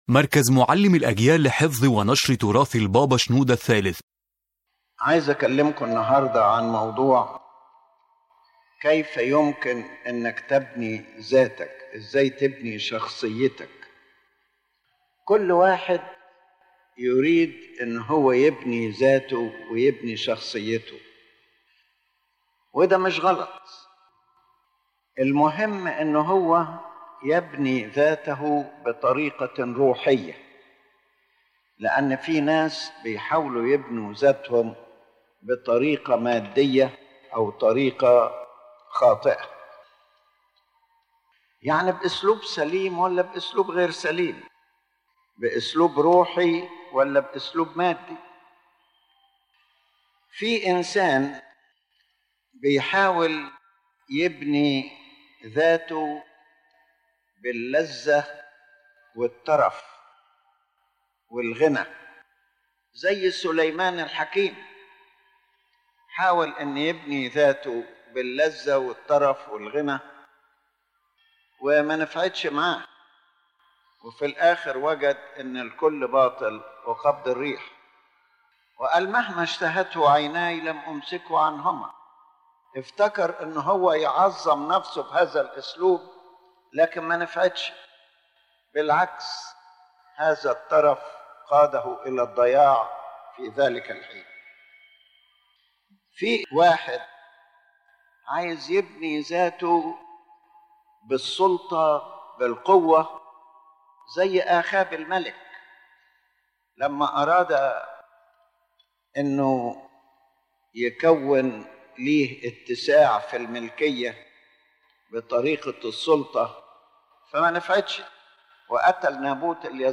This lecture explains that building one’s character is not wrong, but rather something required.